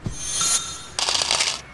cash_collect.ogg